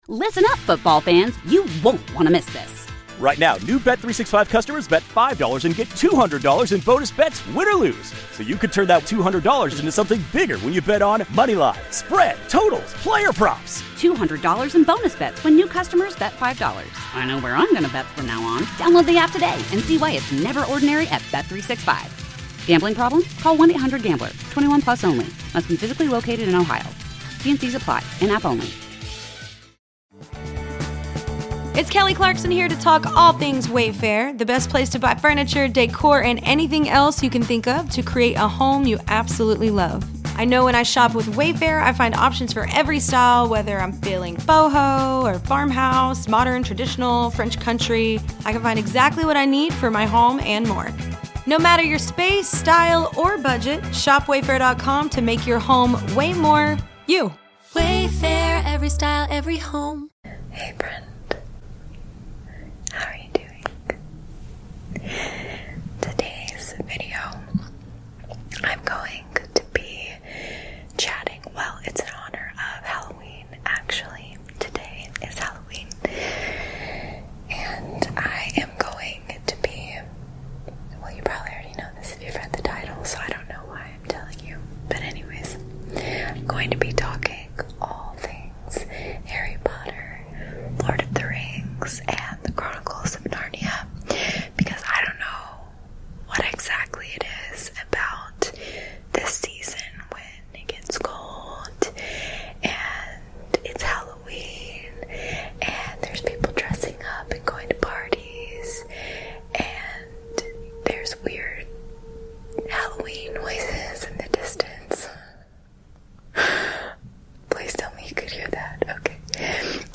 ASMR Whispered Fun Facts